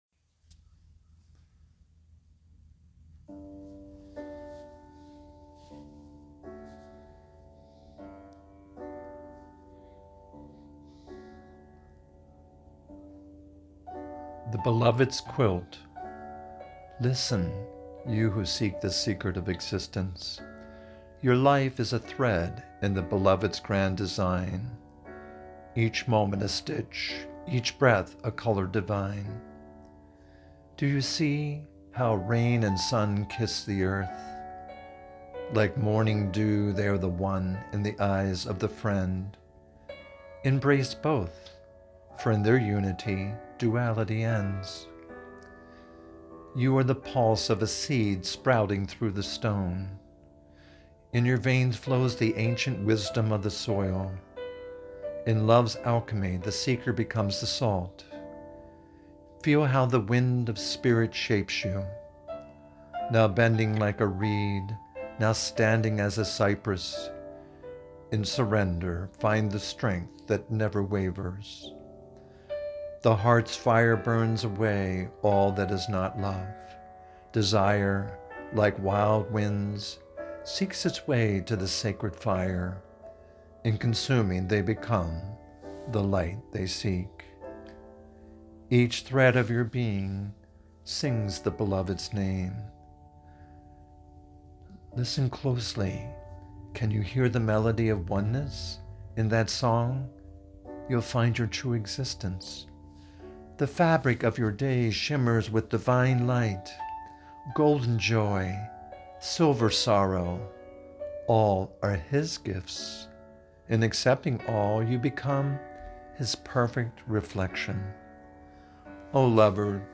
What a rich and mellow voice you have!